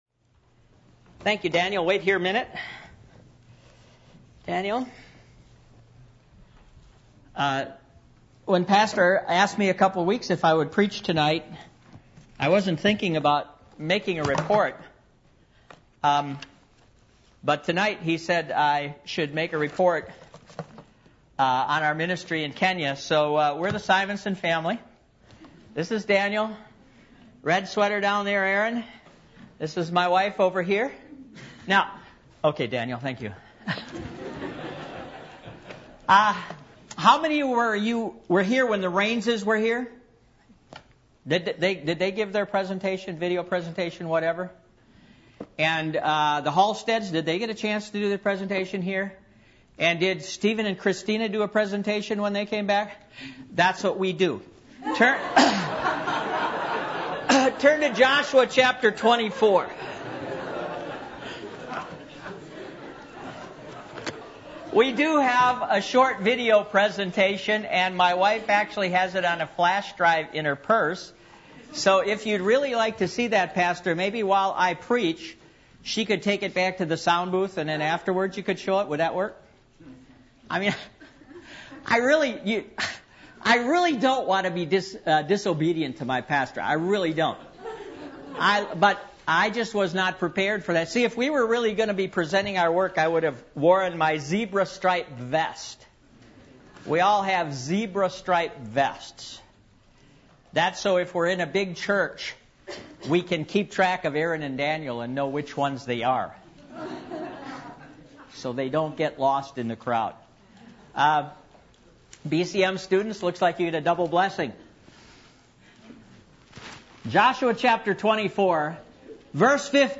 Passage: Joshua 24:1-15 Service Type: Midweek Meeting